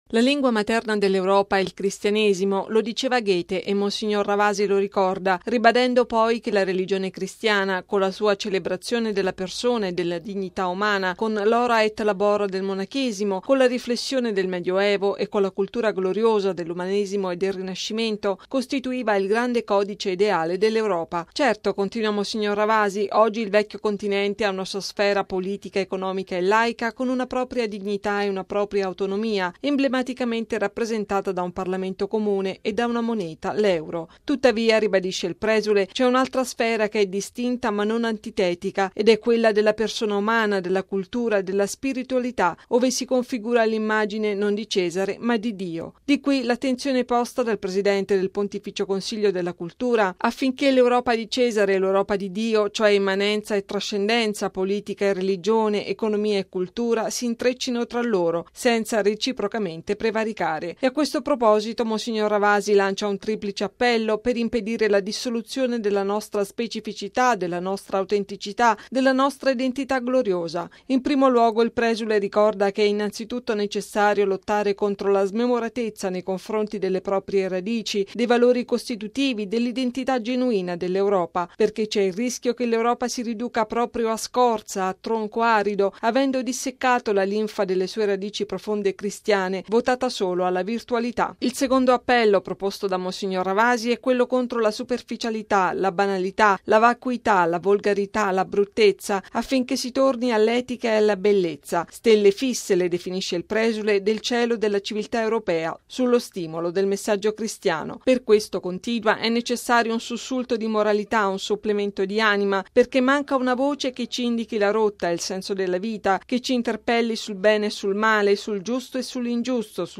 Radio Vaticana - Radiogiornale
◊   Il cristianesimo come “grande codice” ideale dell’Europa: questo il tema centrale della relazione di mons. Gianfranco Ravasi, presidente del Pontifico Consiglio della Cultura e della Pontificia Commissione per i Beni Culturali della Chiesa. Il presule è intervenuto presso la Pontificia Università di Salamanca, in occasione del convegno intitolato “Il patrimonio culturale della Chiesa. La bellezza al servizio dell’evangelizzazione e della cultura”.